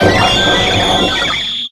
Audio / SE / Cries / ARCEUS.ogg